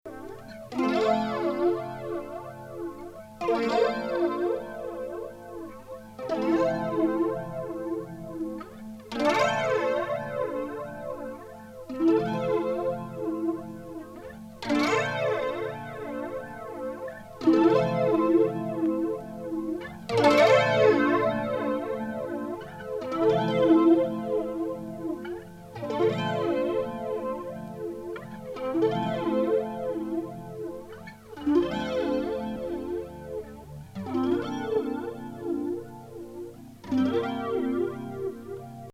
ロマンチックでプログレッシブなギター・インスト組曲
テープ逆回転、トバし過ぎエフェクトまで。